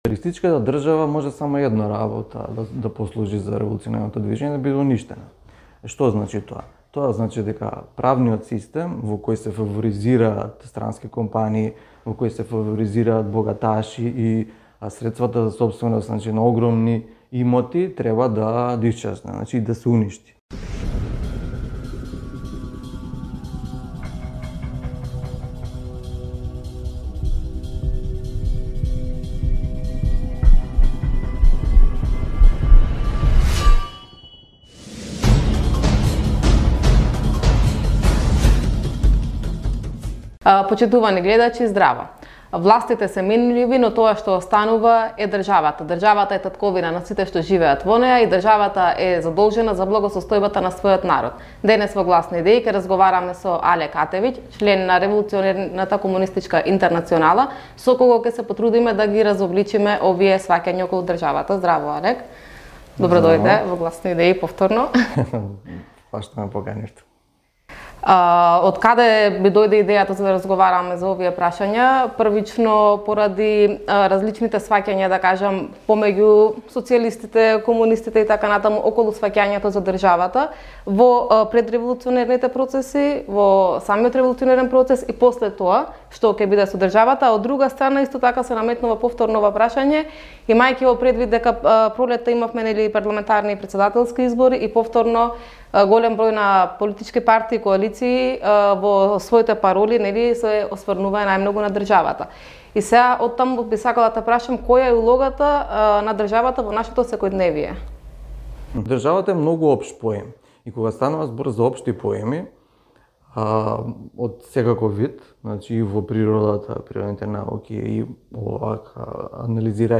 ИНТЕРВЈУ: Што да се прави со државата?